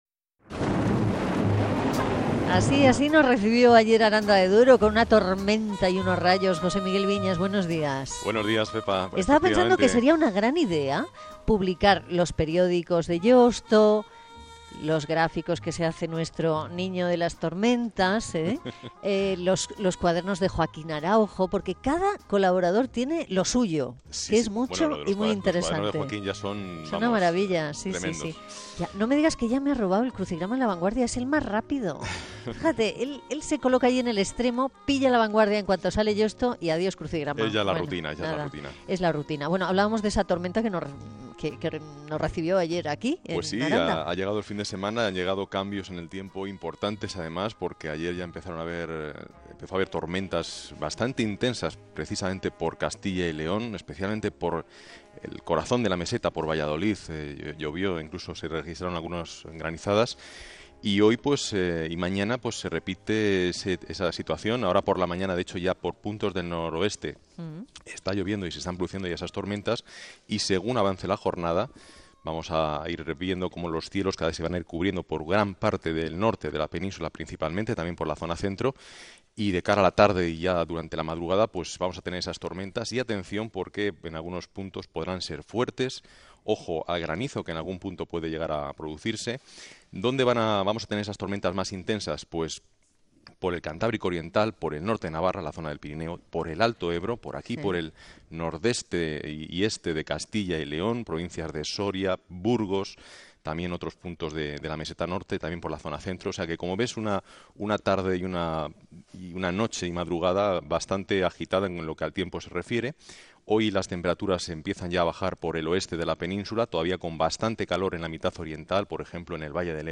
dentro del programa "No es un día cualquiera" que dirige y presenta Pepa Fernández en Radio Nacional de España